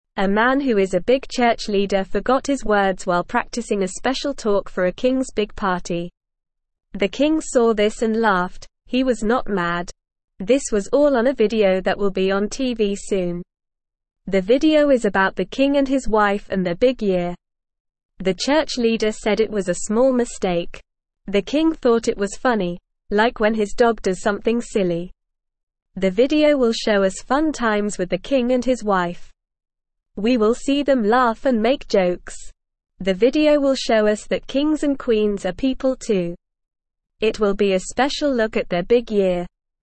Normal
English-Newsroom-Beginner-NORMAL-Reading-The-Kings-Funny-Video-A-Special-Look.mp3